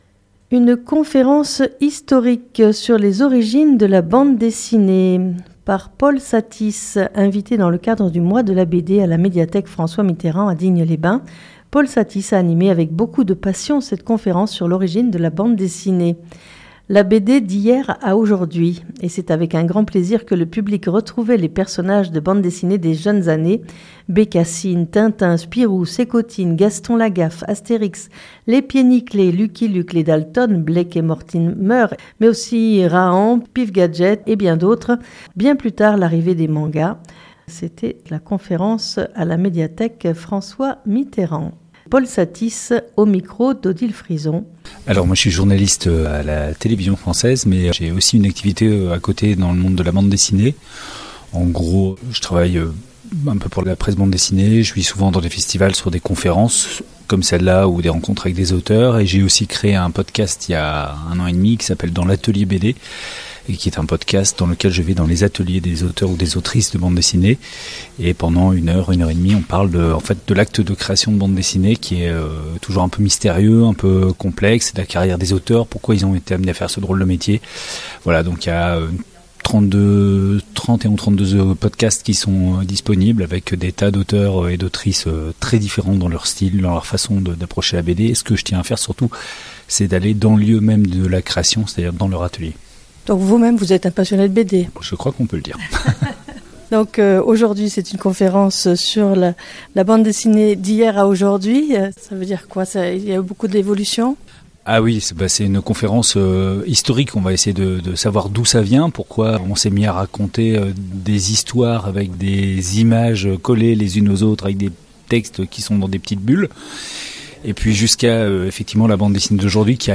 Une conférence historique sur les origines de la Bande Dessinée !